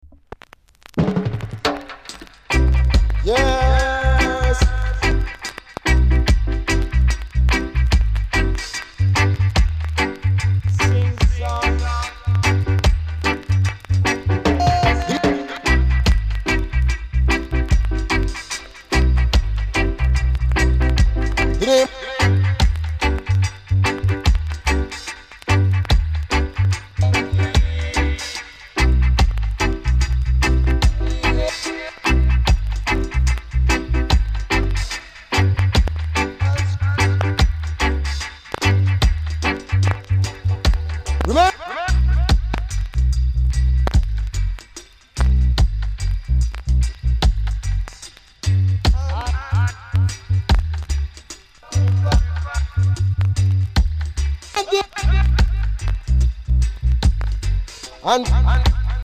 コメント NICE DEEJAY!!!※DUBサイドにプチパチあります。